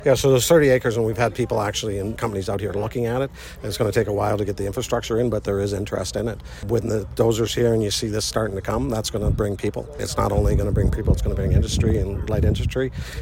There was a sod-turning ceremony at the new home along Highway 37 and Black Diamond Road.
Belleville Mayor Neil Ellis: